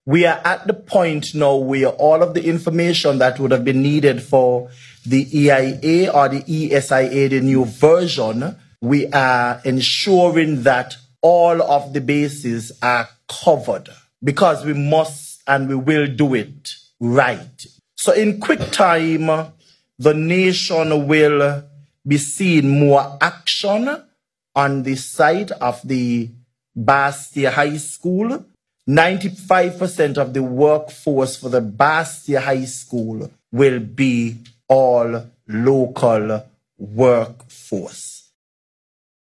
Minister Hanley provided this information and stated:
Deputy Prime Minister, Dr. Geoffrey Hanley.